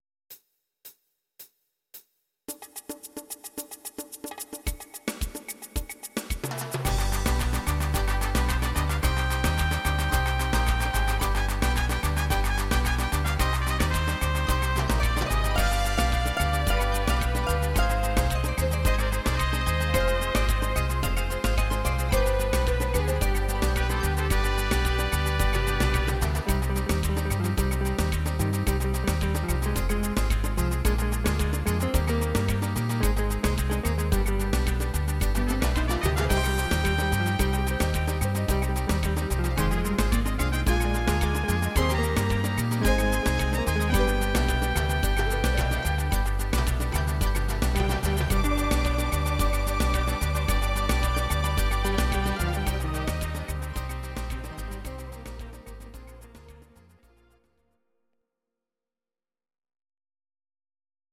Audio Recordings based on Midi-files
Oldies, Ital/French/Span, 1950s